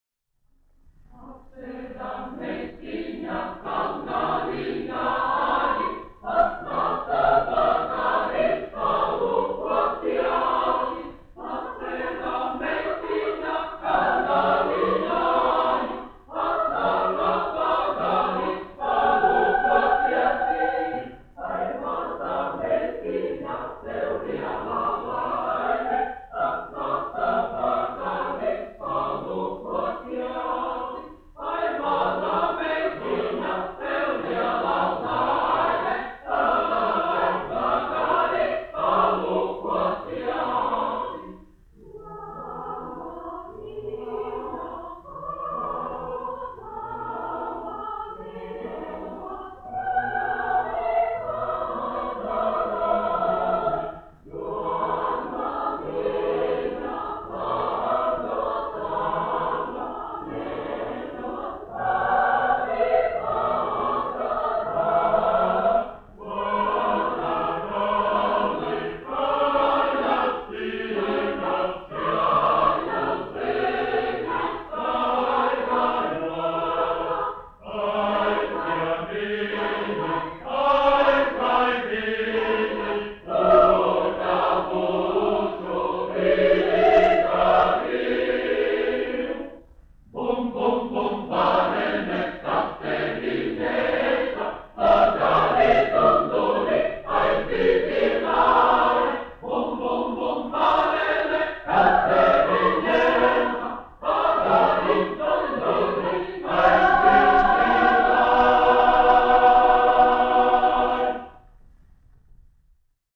Sīkais solis : latv. tautas dziesma
Latvijas Radio Teodora Kalniņa koris, izpildītājs
Kalniņš, Teodors, 1890-1962, diriģents
1 skpl. : analogs, 78 apgr/min, mono ; 25 cm
Latviešu tautasdziesmas
Kori (jauktie)